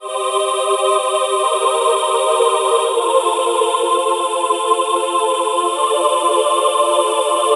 Benedict Monks Eb 127.wav